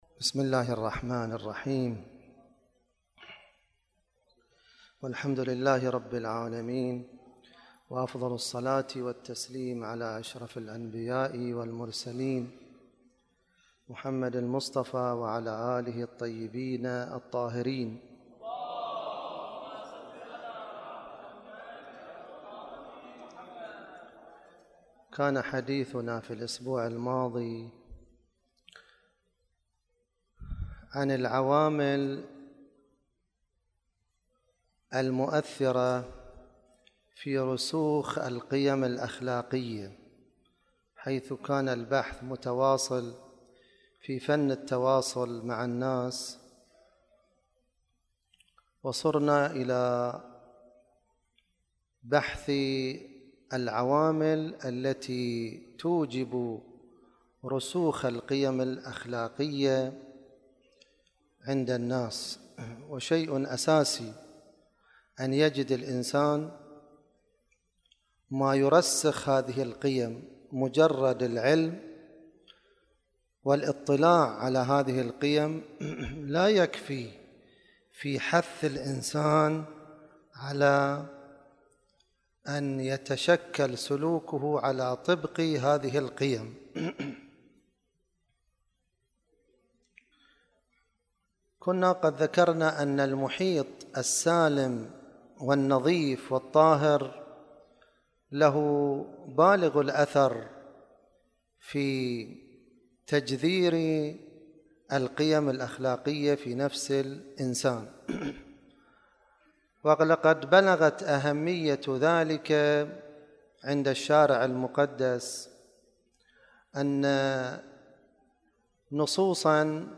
خطبة-الجمعة-29-5-فن-التواصل-ج17.mp3